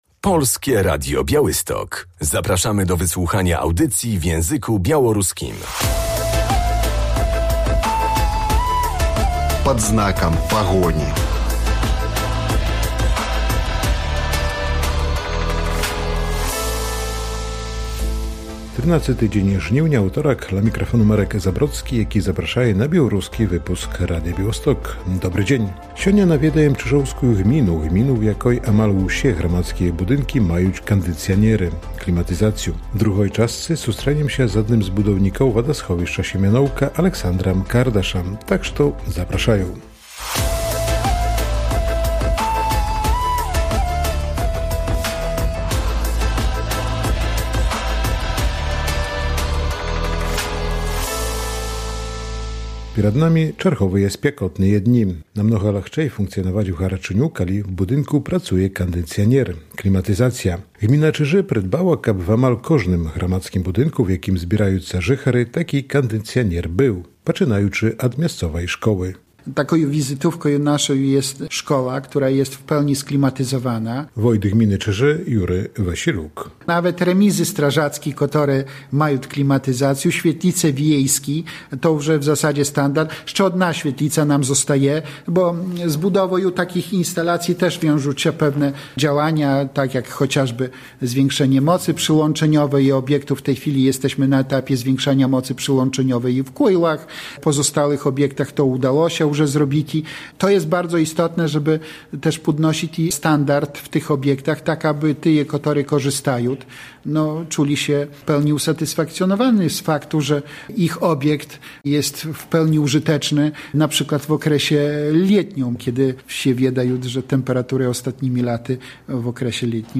Szkoła, remizy, urząd gminy i świetlice wiejskie – wszystkie te budynki gminne w Czyżach, posiadają klimatyzację i fotowoltaikę . O inwestycjach w gminnych rozmawiamy w wójtem Czyż – Jerzym Wasilukiem.